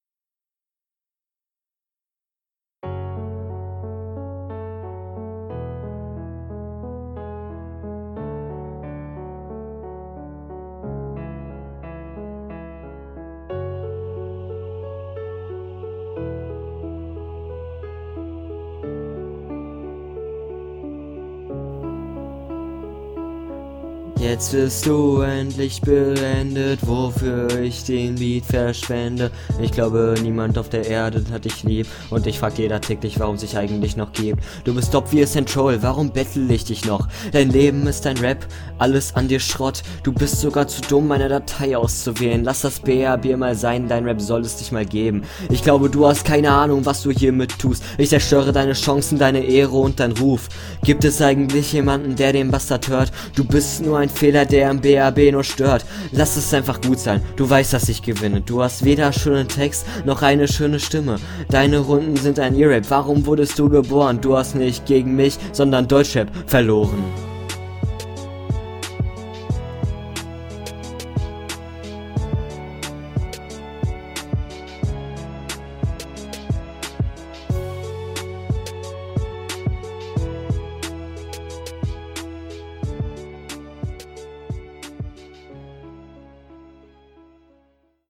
Flow ist okey mehrfach offbeat und das klingt nicht so nice.